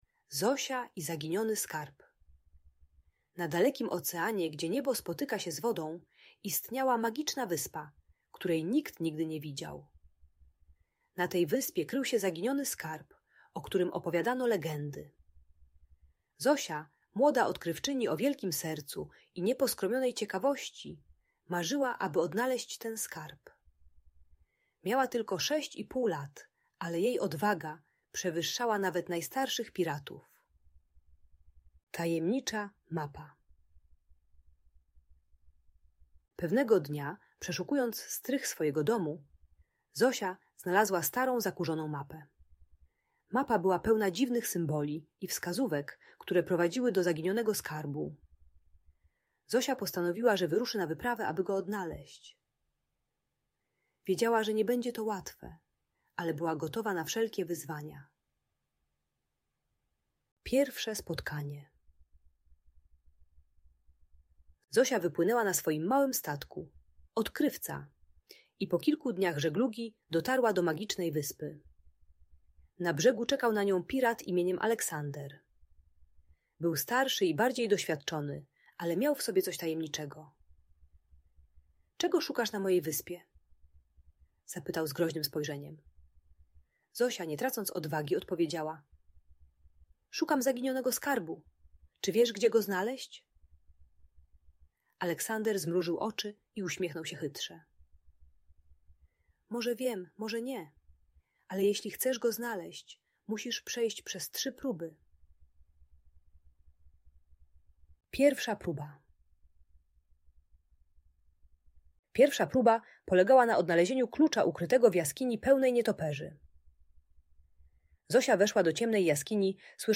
Zosia i Zaginiony Skarb - Magiczna historia o Odwadze i Mądrości - Audiobajka dla dzieci